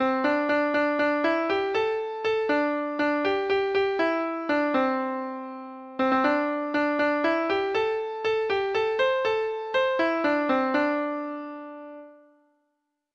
Folk Songs from 'Digital Tradition' Letter Y Young Alan (7)
Traditional Music of unknown author.
Treble Clef Instrument Sheet Music